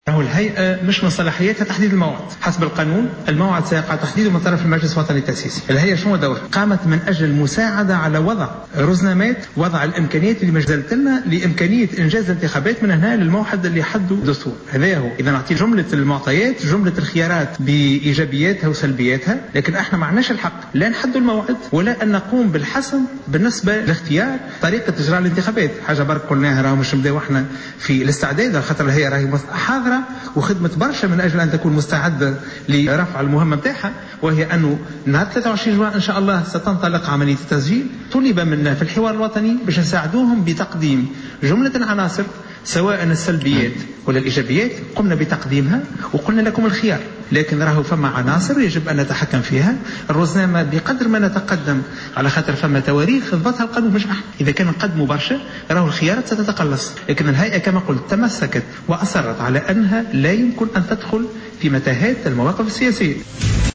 Le président de l’ISIE, Chafik Sarsar, a indiqué ce samedi 24 mai 2014 dans une intervention au micro de Jawhara FM, que l’assemblée nationale constituante fixera la date des prochaines élections.